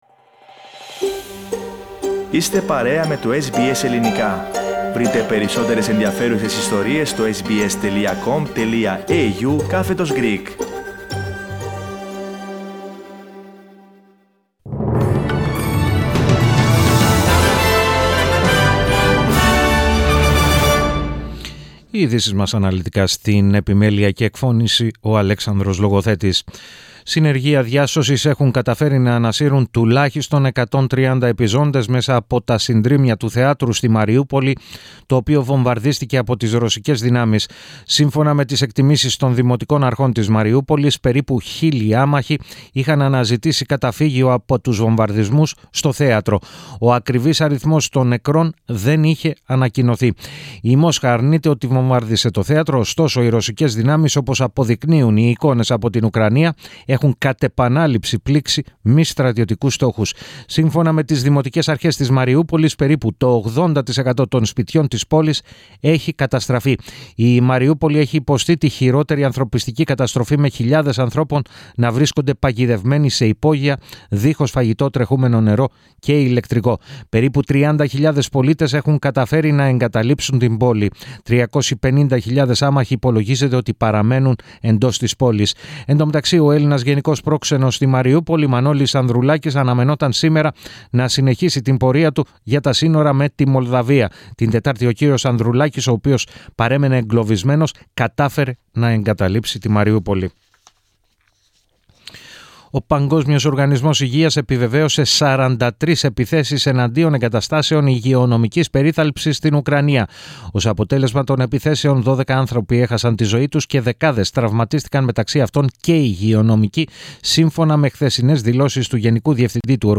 Listen to the main bulletin of the day from the Greek Program.